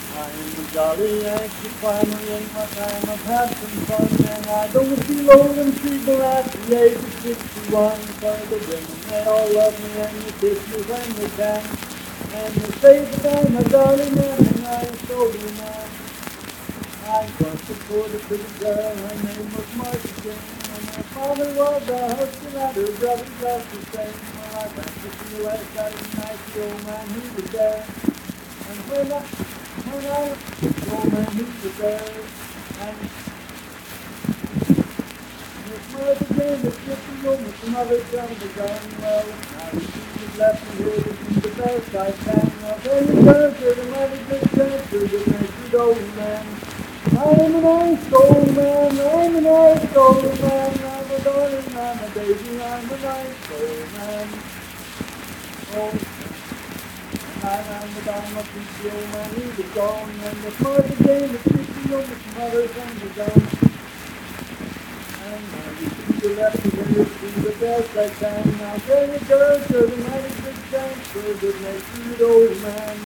Unaccompanied vocal music performance
Verse-refrain 7(4) & R(4).
Voice (sung)